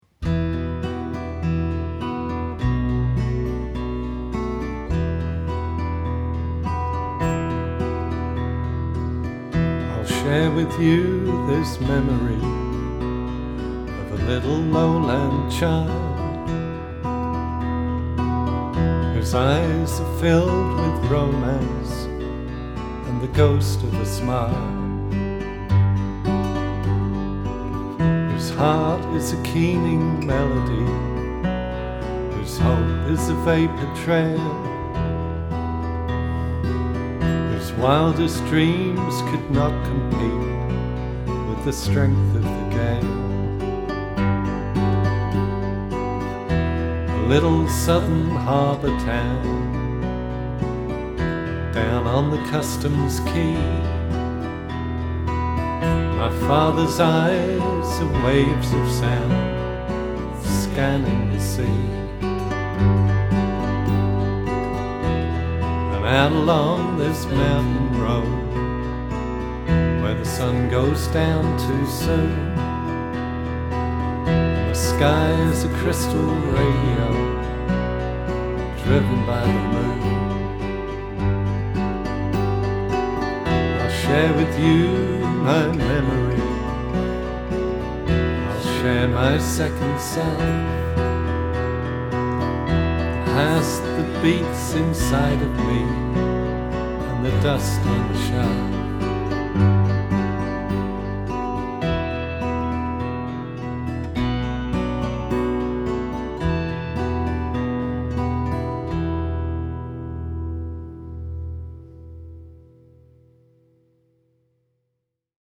vocals, acoustic guitar
electric guitar, vocals
bass, vocals
fiddle
in Nottingham